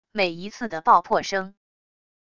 每一次的爆破声wav音频